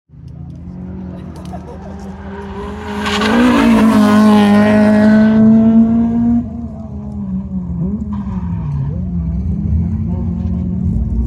FULL SEND KEEPING HER FLAT ABIT OF PASSENGERFROMT WHEEL LIFT AND WITH A BIT OF BRAKE LOCK UP ALL FUN